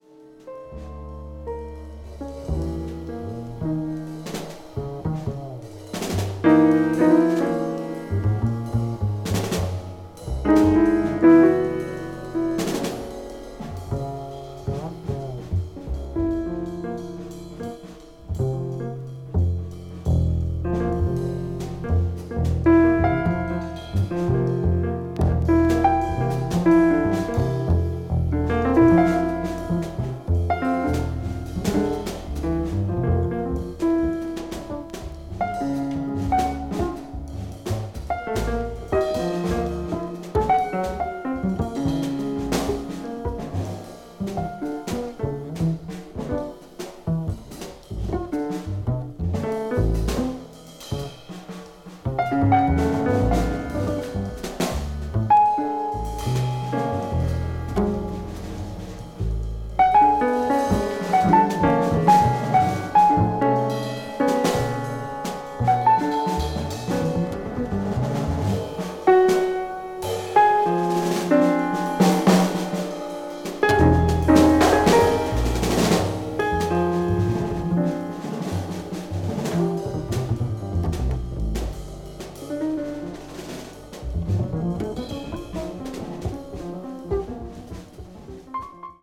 A brilliant deep sessions.